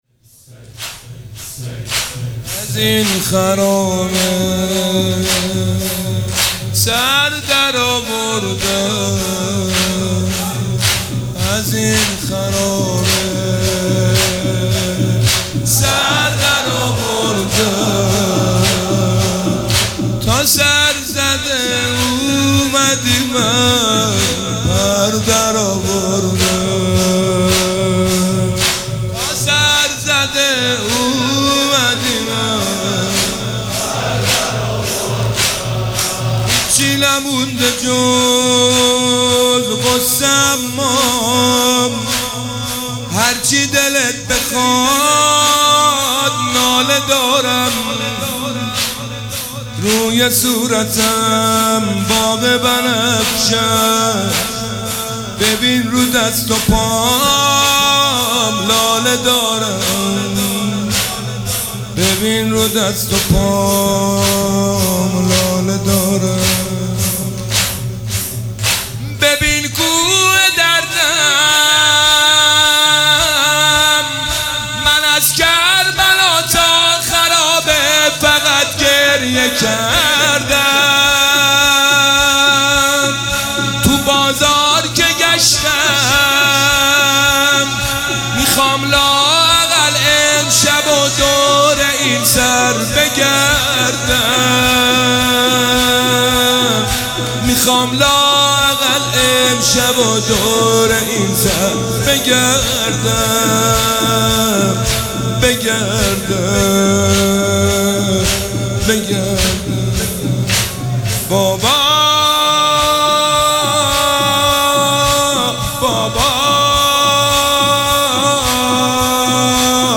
دانلود فایل صوتی مداحی زمینه حضرت رقیه